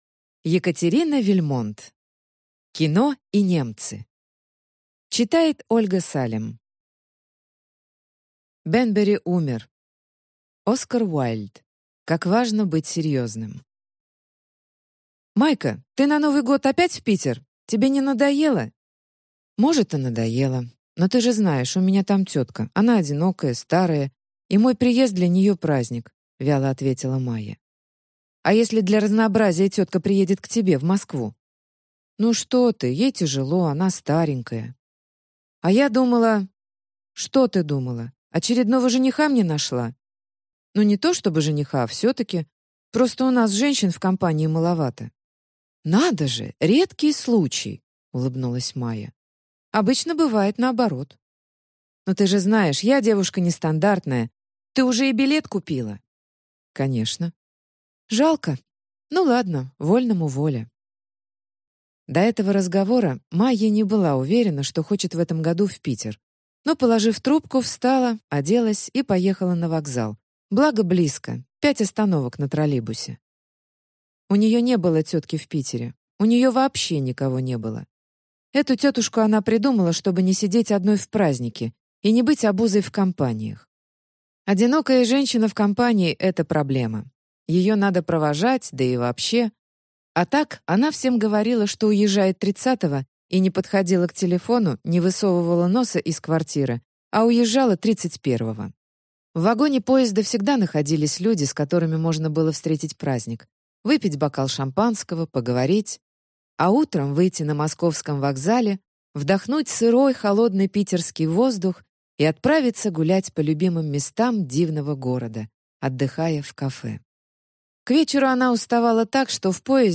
Аудиокнига Кино и немцы!